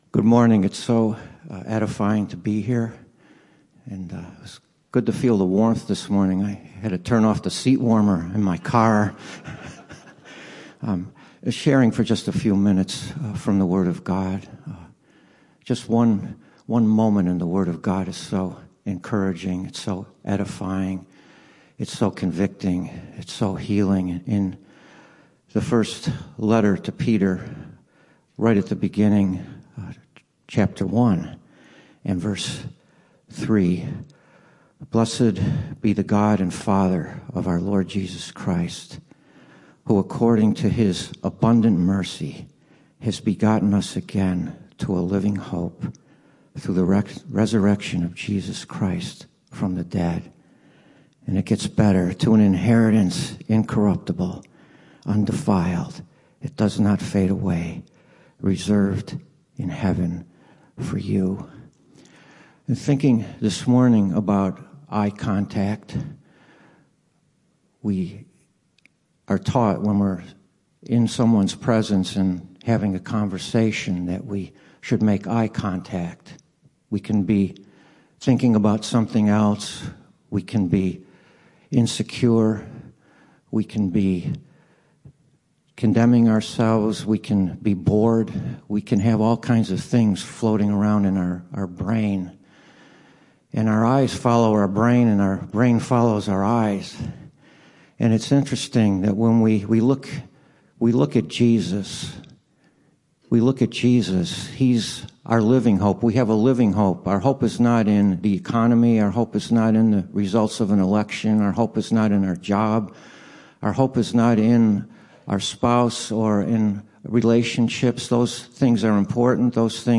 Mailbag